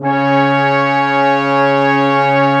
55m-orc10-C#3.wav